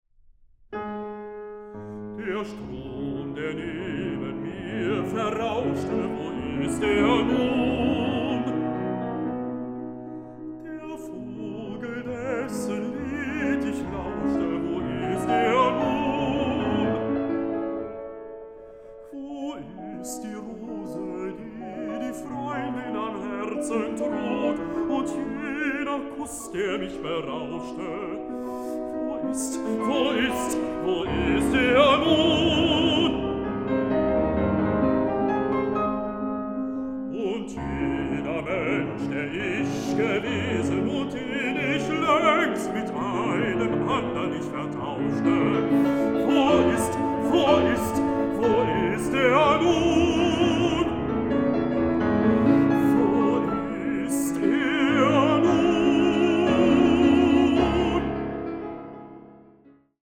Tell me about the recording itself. Recording: Mendelssohn-Saal, Gewandhaus Leipzig, 2025